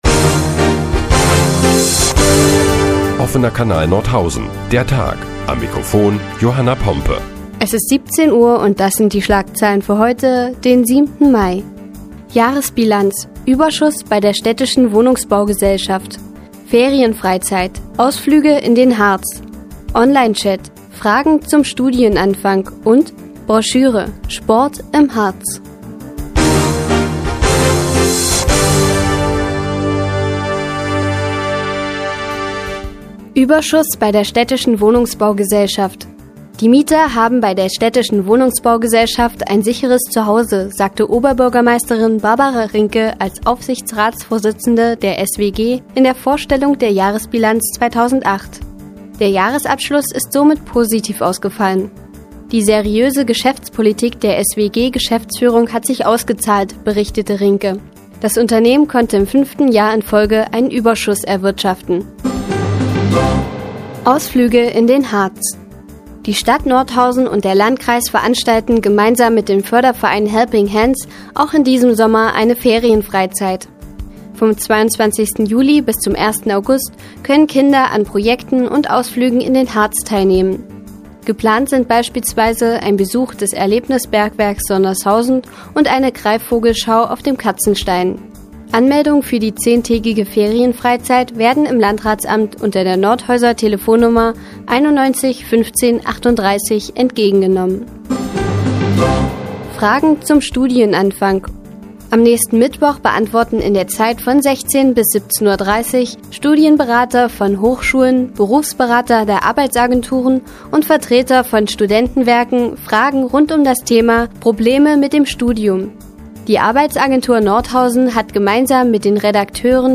Die tägliche Nachrichtensendung des OKN ist nun auch in der nnz zu hören. Heute geht es unter anderem um Ausflüge und Sport im Harz.